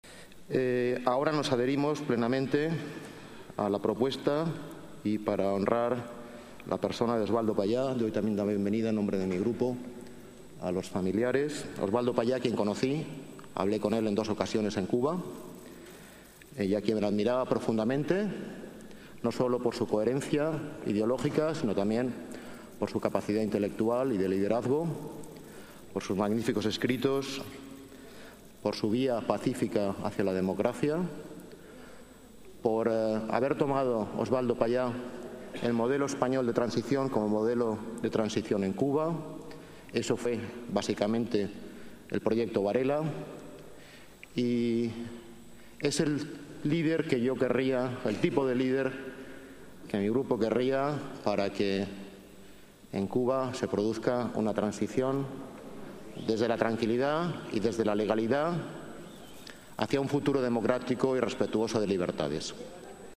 Nueva ventana:Declaraciones del delegado Fernando Villalonga en el Pleno